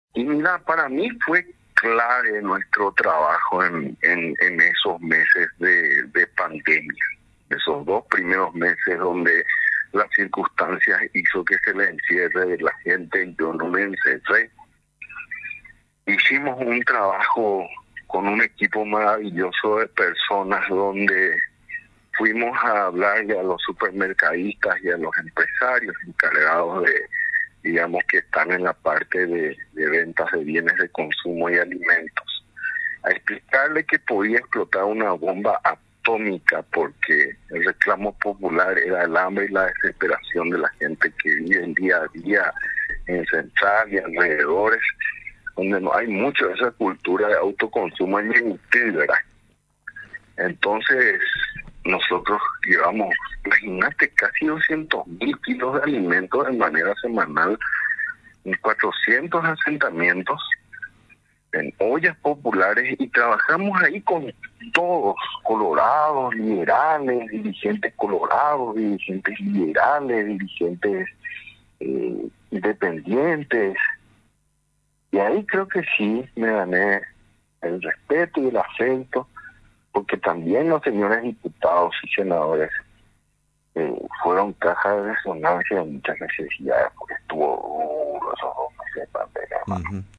En conversación con Radio Nacional San Pedro 105.9 FM, el defensor Godoy, destacó que su reelección se logró, mediante las labores que lleva adelante al frente de la cartera estatal y principalmente durante esta pandemia, cuando las tareas de los funcionarios no se paralizaron, ya que con su equipo de trabajo se ganó el respeto de la ciudadanía. Reafirmó su compromiso en proseguir con su trabaja al frente de la institución del Estado.